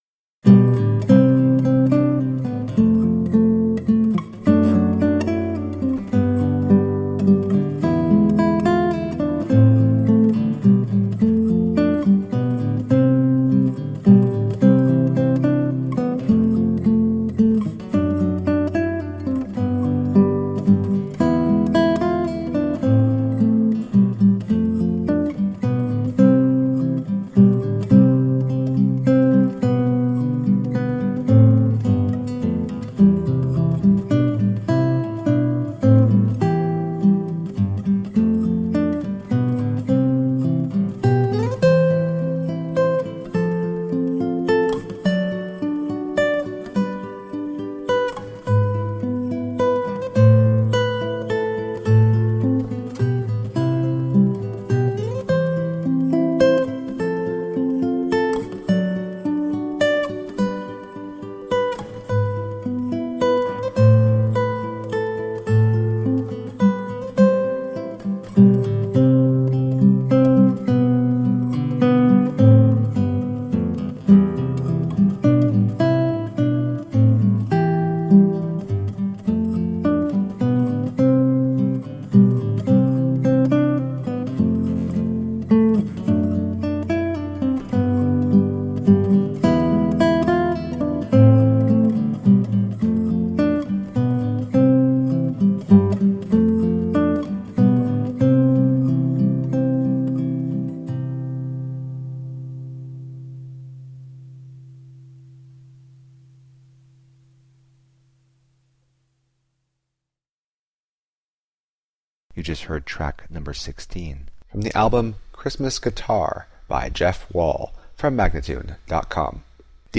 Acoustic new age and jazz guitar..